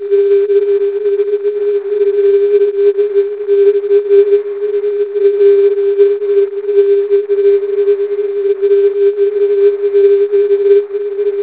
With a little assistance from a DSP audio filter, the signal stands out more clearly, although at the same time it gets "blurred" by ringing because of the static crashes. The second WAVE file below is the same signal and noise, passed through a filter bandwidth of approximately 50 Hz in Cool Edit.
CW at -18 dB, after DSP audio filter with 50 Hz bandwidth centered at 400 Hz